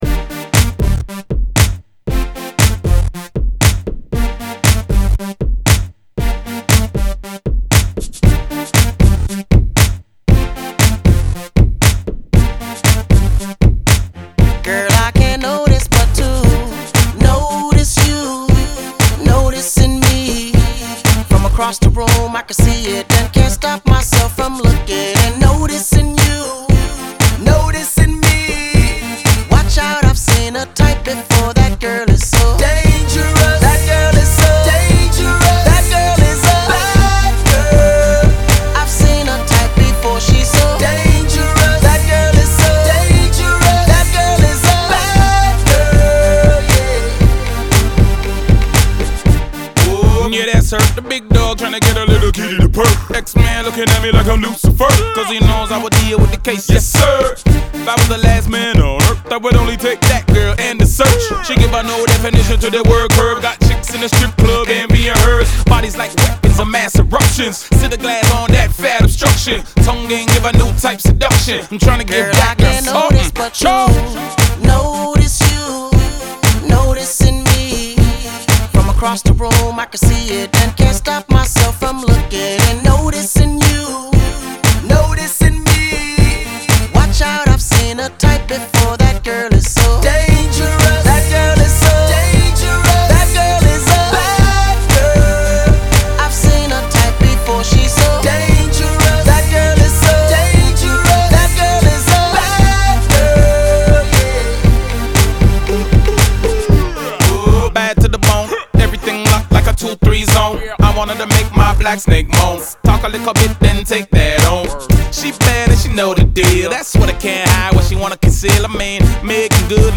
энергичная хип-хоп композиция